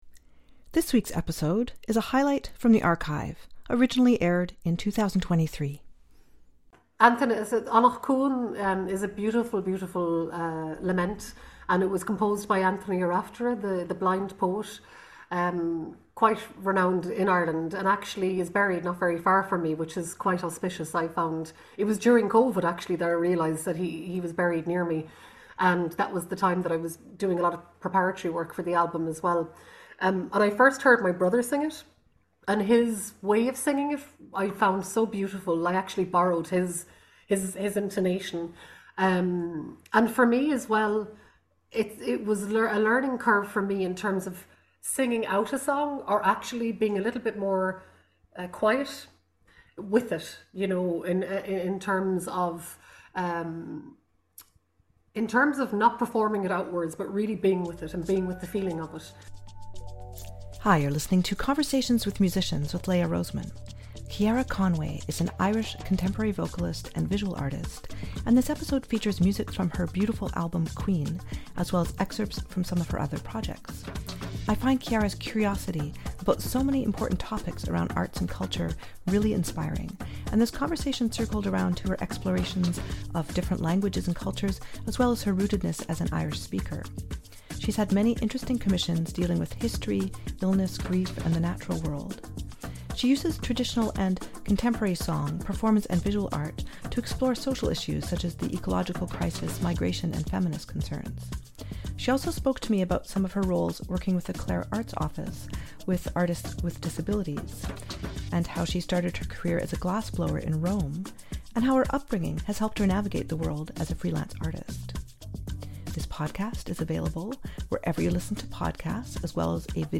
There’s a fascinating variety to a life in music; this series features wonderful musicians worldwide with in-depth conversations and great music. Many episodes feature guests playing music spontaneously as part of the episode or sharing performances and albums.